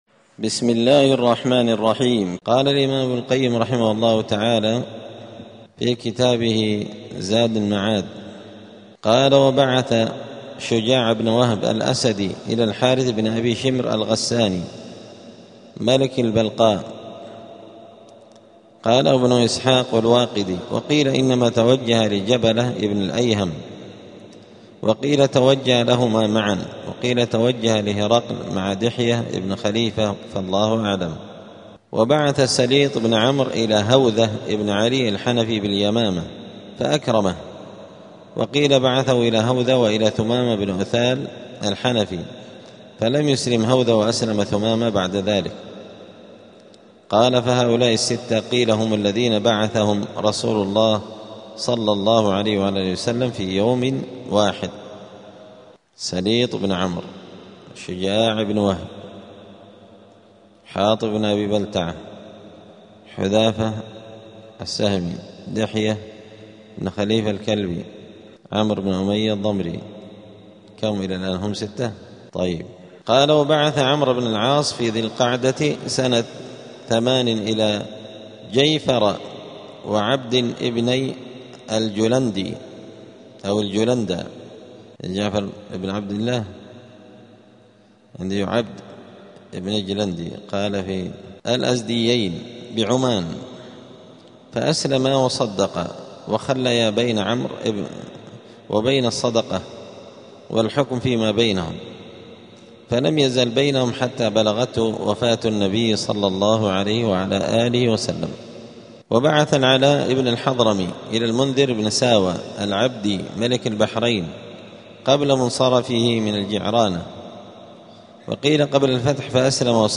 *الدرس الواحد والعشرون (21) {ﻓﺼﻞ ﻓﻲ ﻛﺘﺒﻪ ﻭﺭﺳﻠﻪ ﺻﻠﻰ اﻟﻠﻪ ﻋﻠﻴﻪ ﻭﺳﻠﻢ ﺇﻟﻰ اﻟﻤﻠﻮﻙ}.*
دار الحديث السلفية بمسجد الفرقان قشن المهرة اليمن